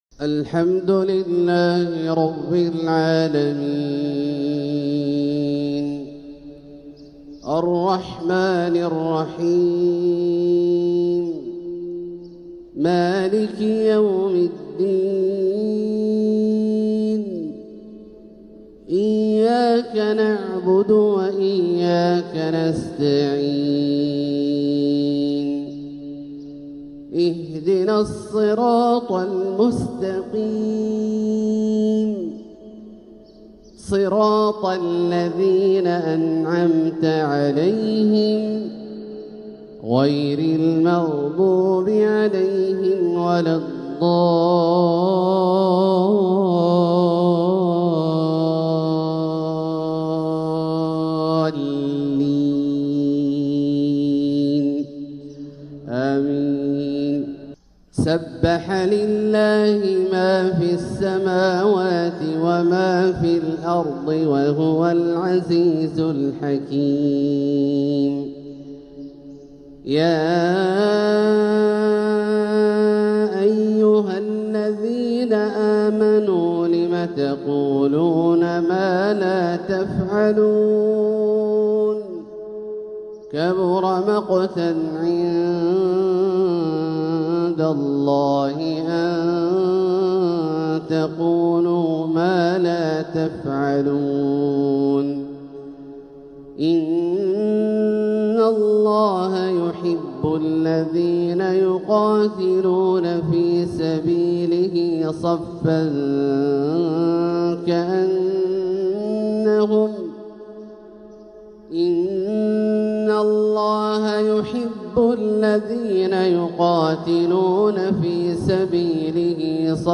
القطف الجني لتلاوات الشيخ عبدالله الجهني | شهر رجب 1446هـ "الحلقة السادسة والسبعون" > سلسلة القطف الجني لتلاوات الشيخ عبدالله الجهني > الإصدارات الشهرية لتلاوات الحرم المكي 🕋 ( مميز ) > المزيد - تلاوات الحرمين